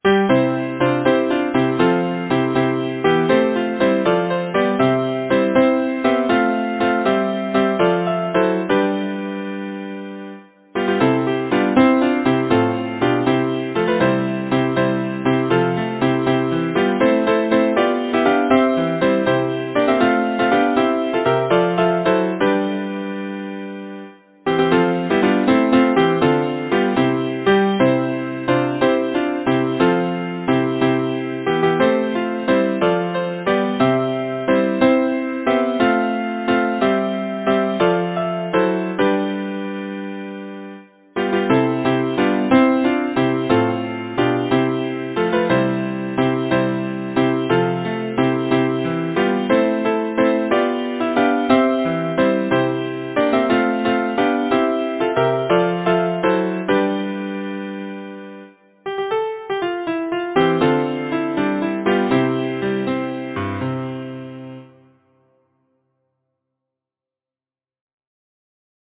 Title: The young May moon Composer: Charles Harford Lloyd Lyricist: Thomas Moore Number of voices: 4vv Voicing: SATB Genre: Secular, Partsong
Language: English Instruments: A cappella